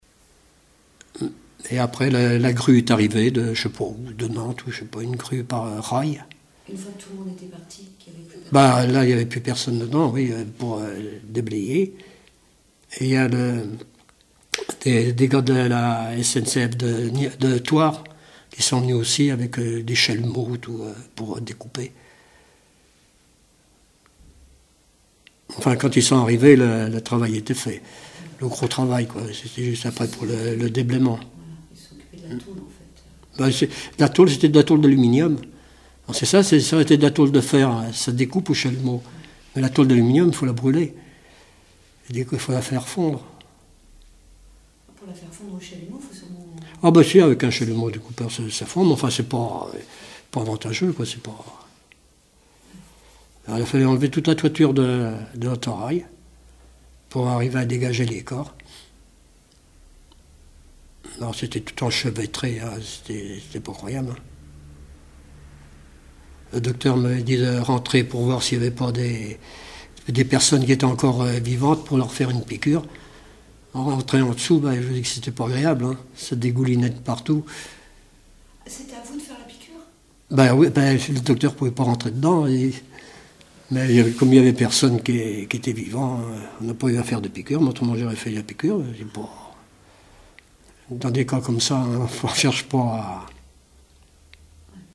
Témoignages d'un ancien sapeur-pompier
Catégorie Témoignage